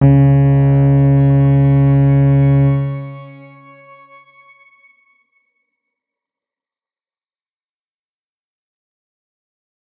X_Grain-C#2-mf.wav